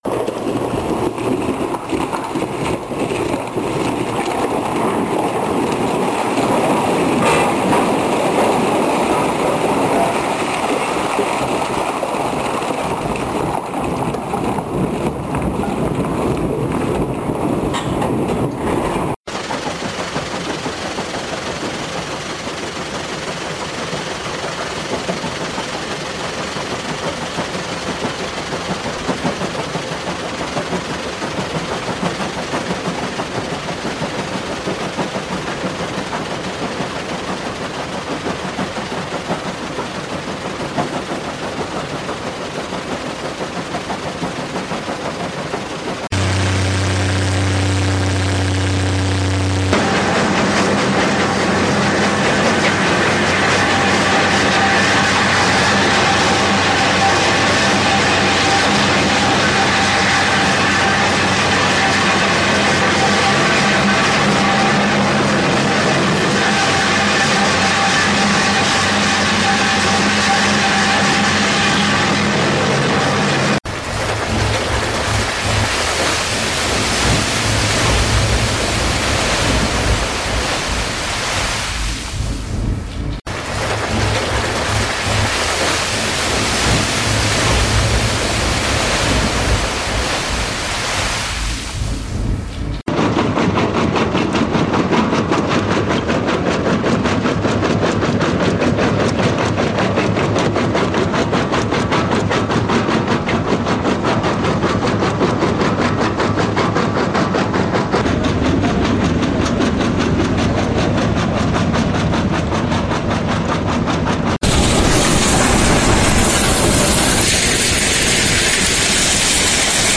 Såhär låter en stenkross!
Klicka här för att lyssna hur det låter från en stenkross.